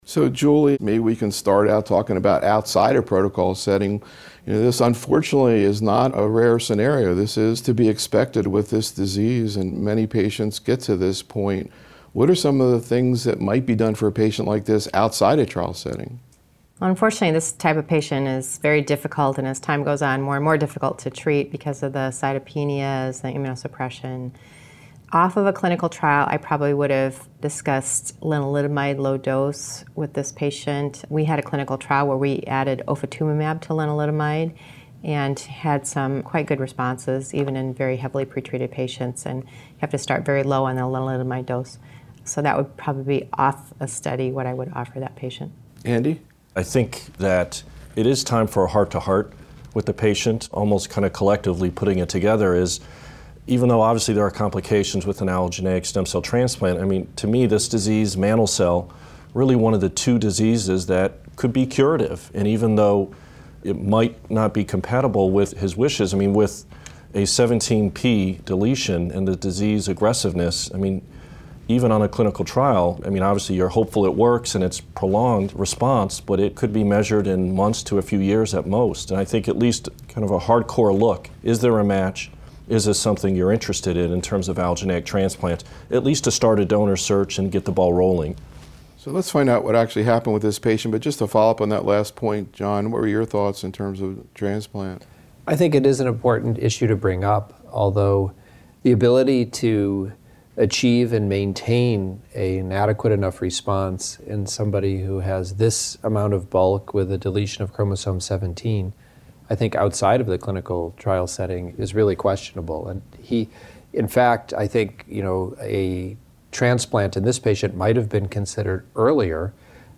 The roundtable discussion focused on key presentations and papers and actual cases managed in the practices of the faculty where these data sets factored into their decision-making.